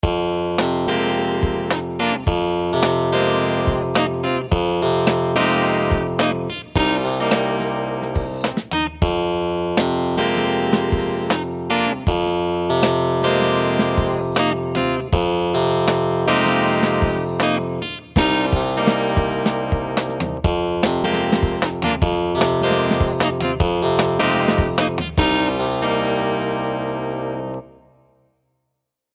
独一无二的键盘乐句
使用珍贵的复古设备进行录制
录音室灵魂
Rhodes，Wurlitzer和Clavinet钢琴
声音类别: 键盘乐句